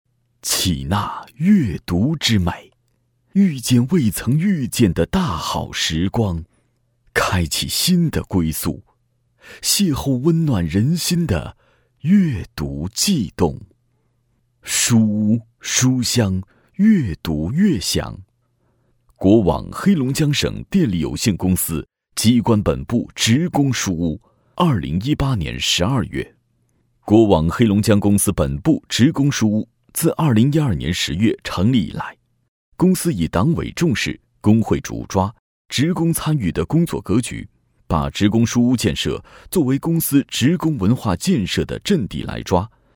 大气浑厚 企业专题,人物专题,医疗专题,学校专题,产品解说,警示教育,规划总结配音
大气浑厚质感男音，磁性稳重、年轻。